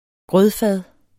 Udtale [ ˈgʁœð- ]